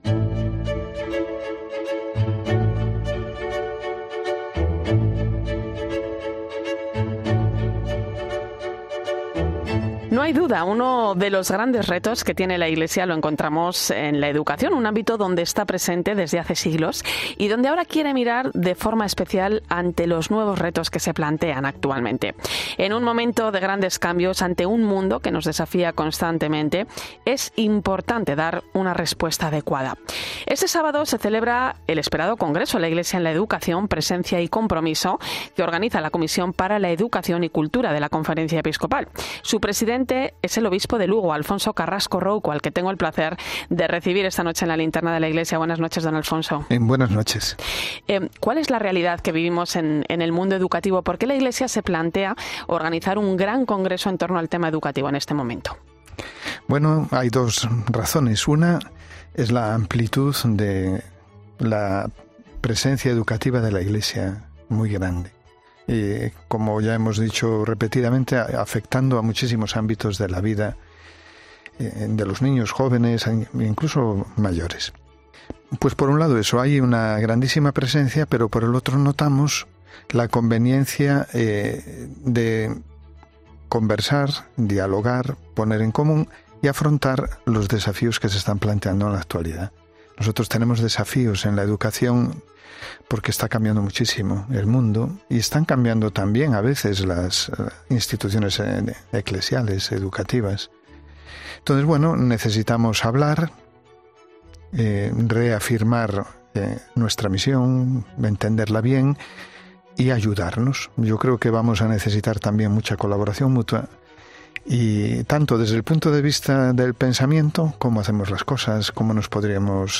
En La Linterna de la Iglesia averiguamos las claves del congreso 'La Iglesia en la Educación' con Mons. Alfonso Carrasco, presidente de la comisión...